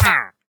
Minecraft Version Minecraft Version latest Latest Release | Latest Snapshot latest / assets / minecraft / sounds / mob / wandering_trader / hurt1.ogg Compare With Compare With Latest Release | Latest Snapshot
hurt1.ogg